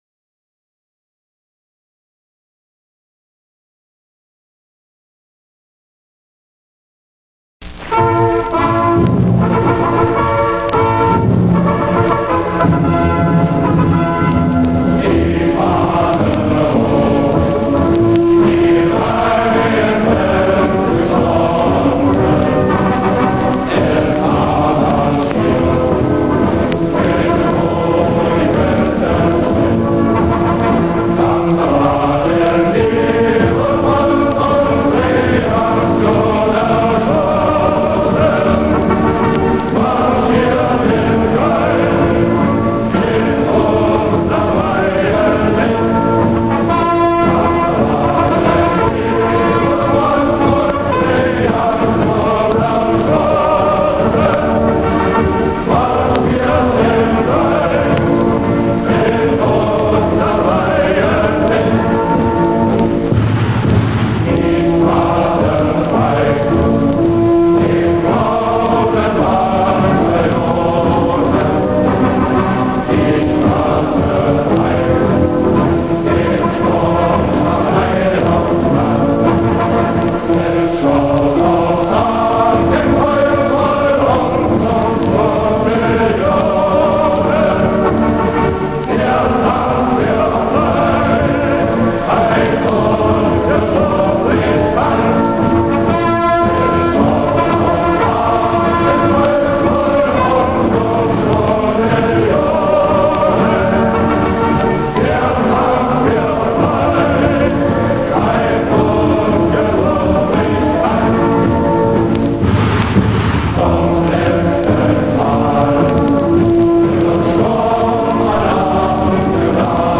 >SA Marschlied